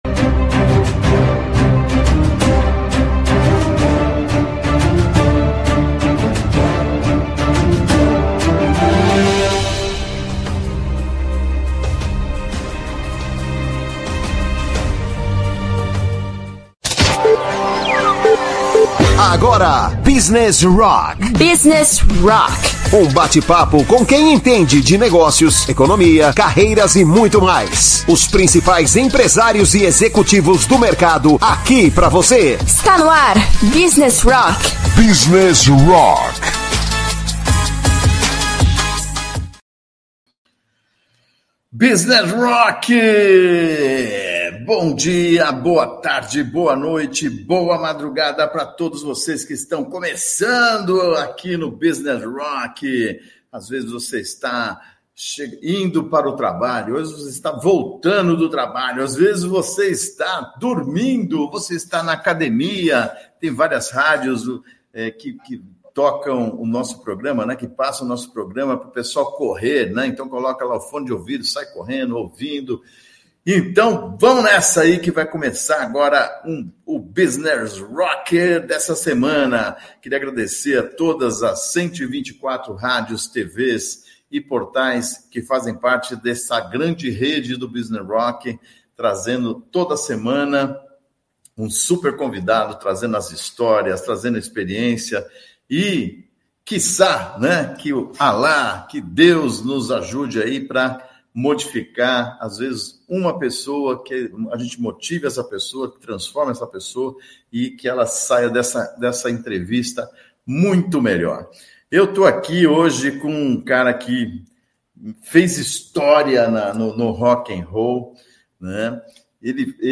Business Rock entrevista